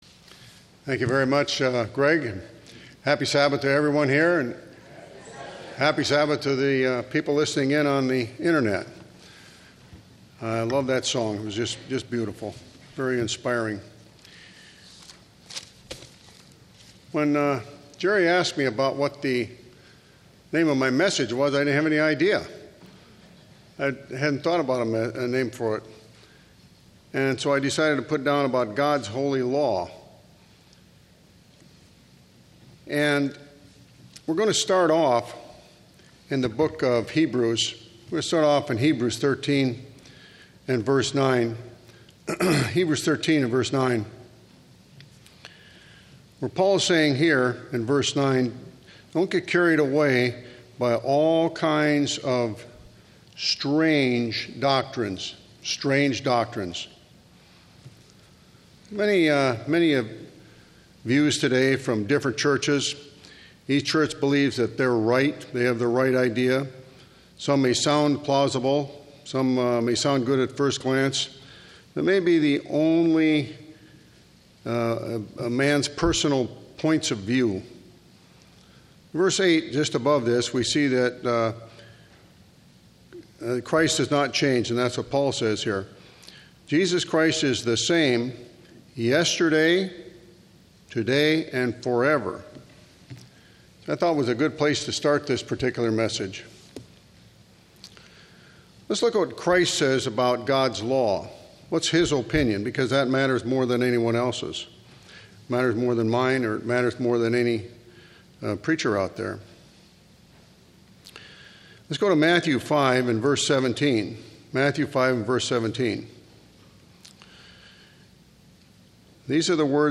Given in Orange County, CA
UCG Sermon Studying the bible?